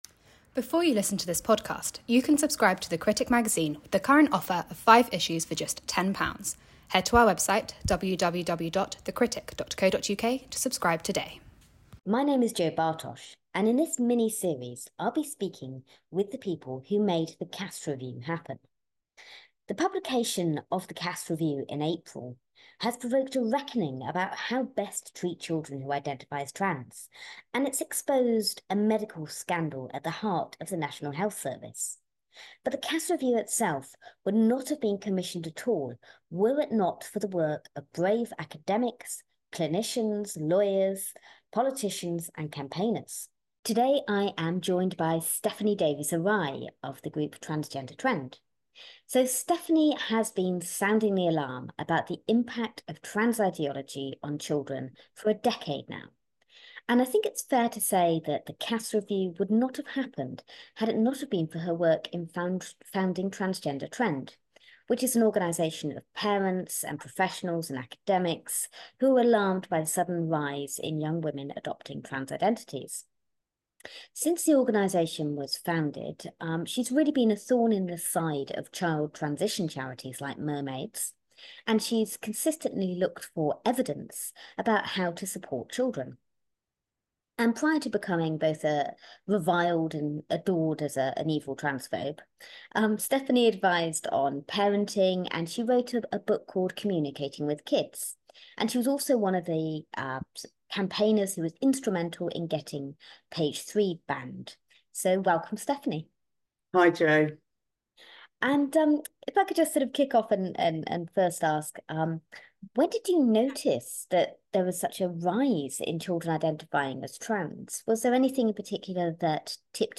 interviewing some of the people who helped to make it happen.